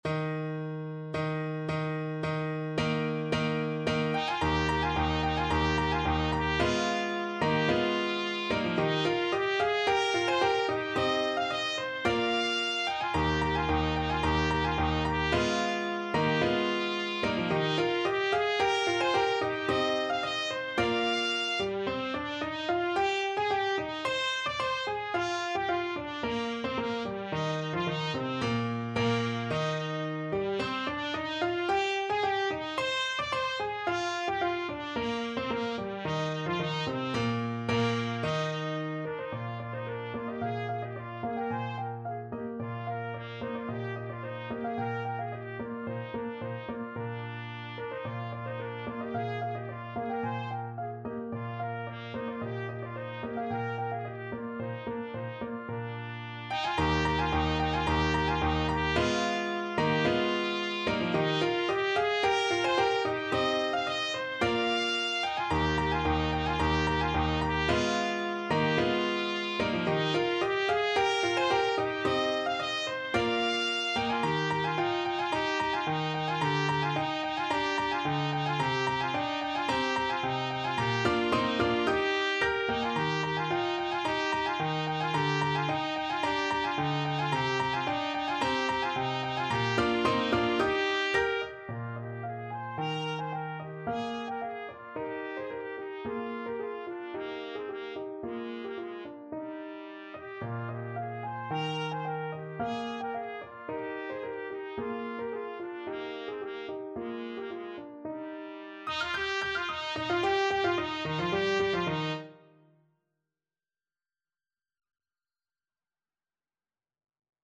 Trumpet
Eb major (Sounding Pitch) F major (Trumpet in Bb) (View more Eb major Music for Trumpet )
= 110 Allegro di molto (View more music marked Allegro)
2/2 (View more 2/2 Music)
Classical (View more Classical Trumpet Music)